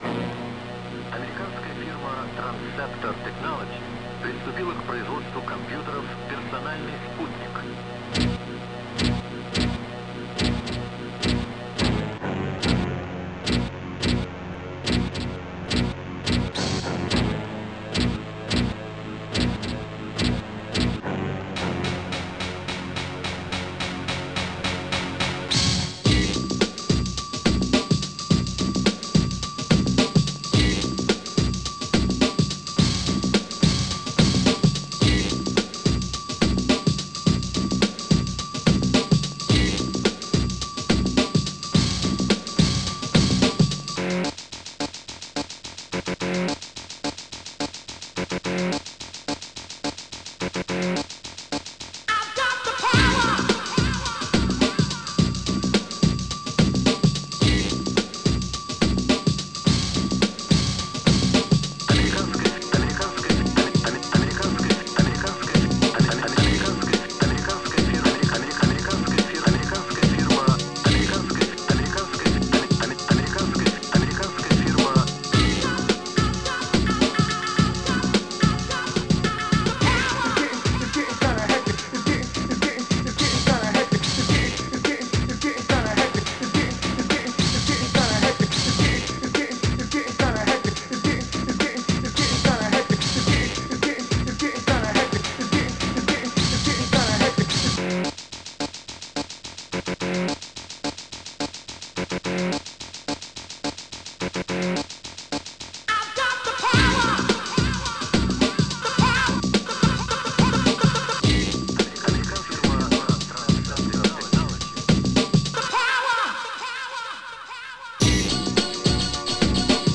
ripped this remix
my synthesiser